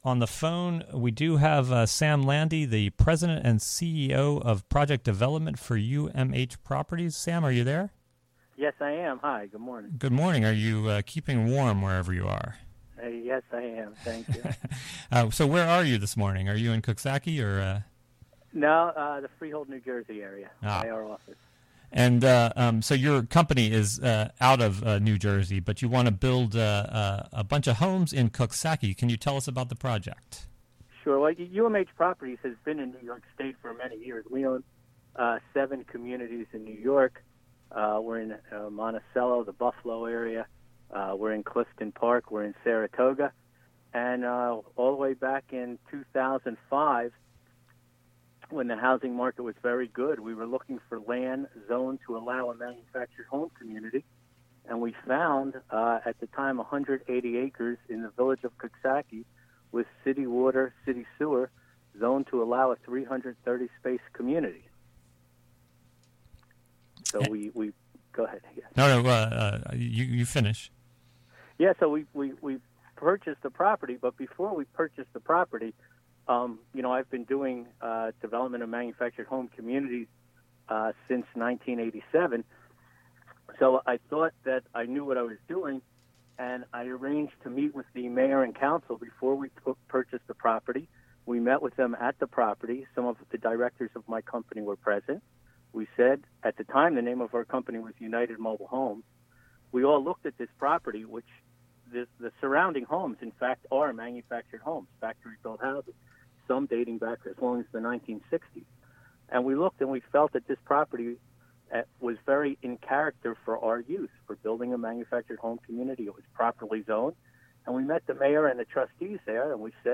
11am The WGXC Morning Show is a radio magazine show fea...